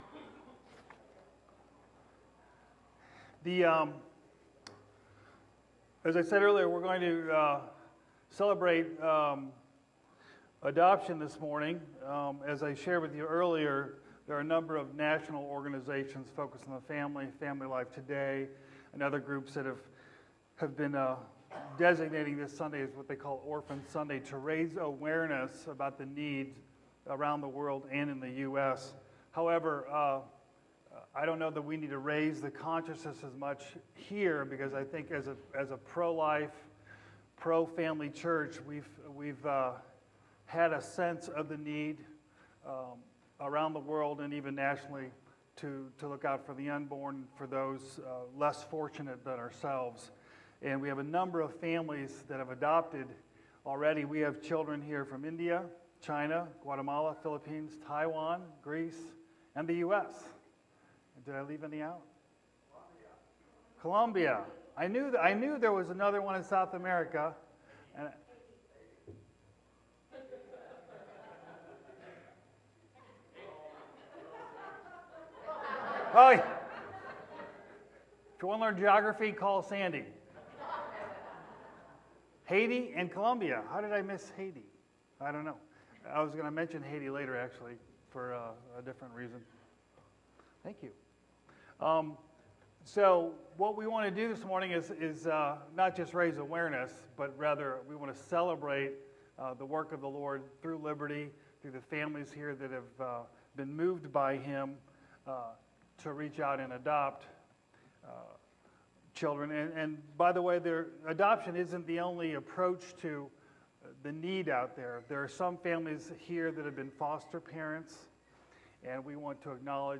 Members of the church who have adopted children speak about their experiences and the importance of adoption.